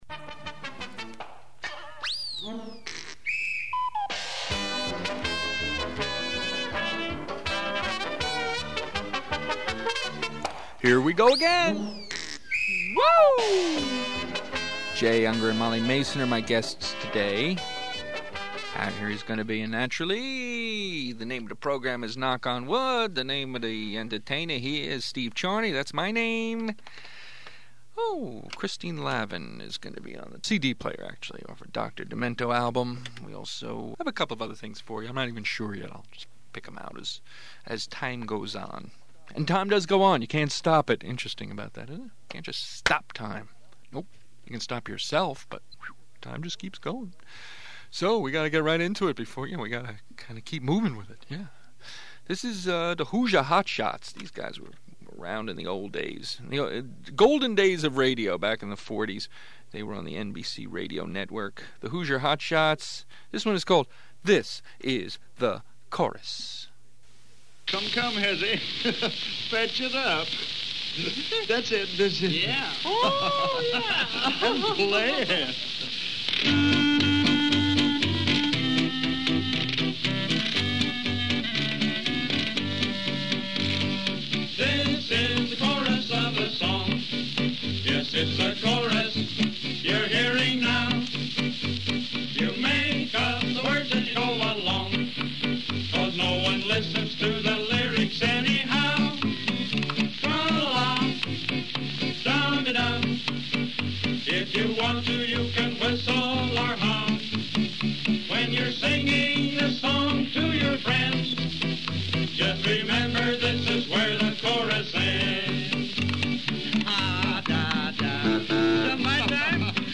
Knock On Wood Comedy Show
Jay Ungar and Molly Mason are guests